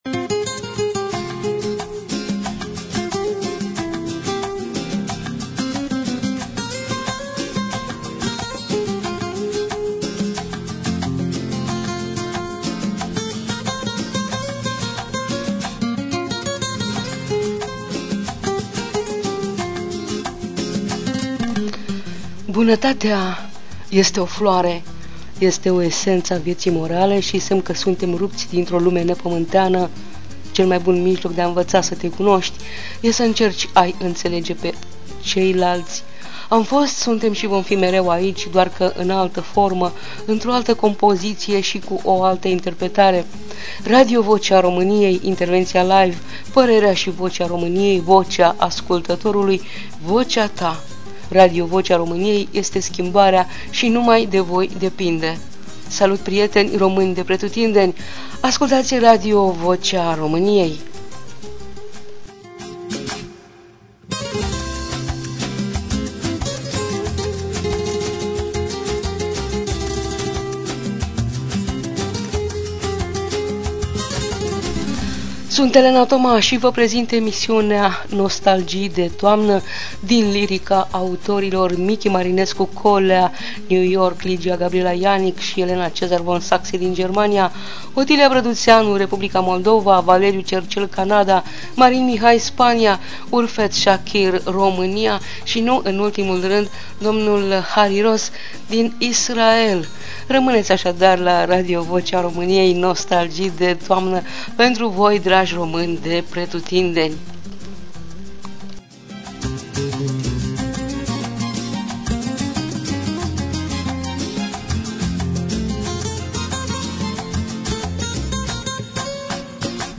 Autograf muzical
Voce: Scriitoarea